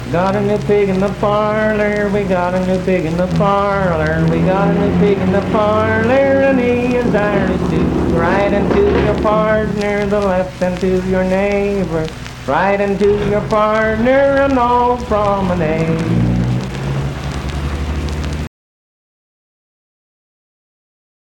Unaccompanied vocal performance
Dance, Game, and Party Songs
Voice (sung)